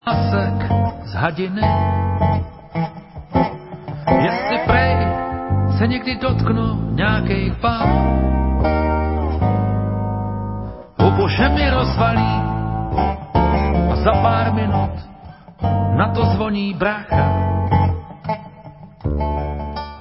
Reedice alba ze zlatého fondu naší bluegrassové muziky!